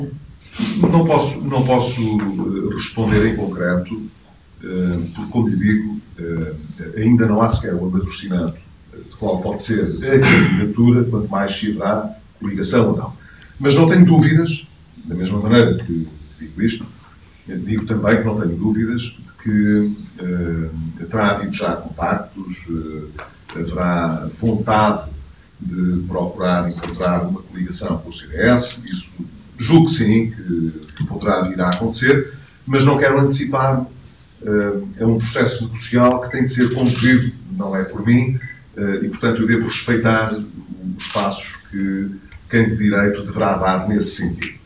Declarações de Pedro Passos Coelho em Viana do Castelo